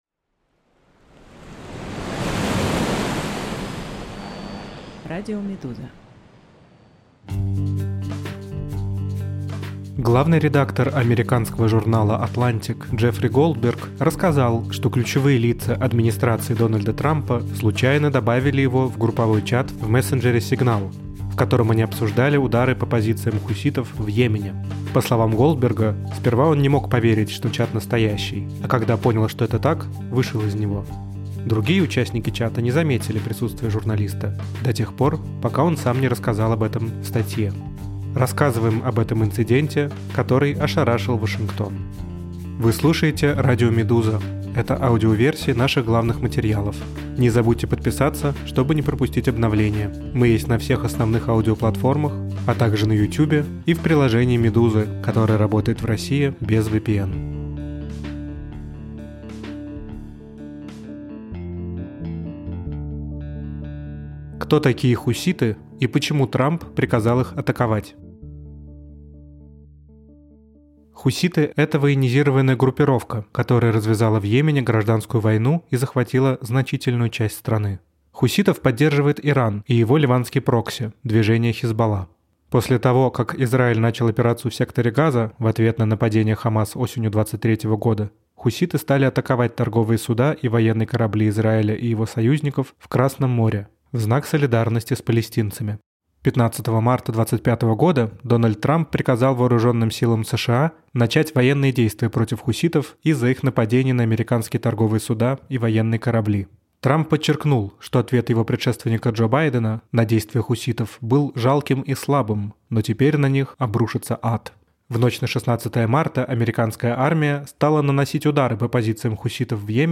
Аудиоверсии главных текстов «Медузы». Расследования, репортажи, разборы и другие материалы — теперь и в звуке.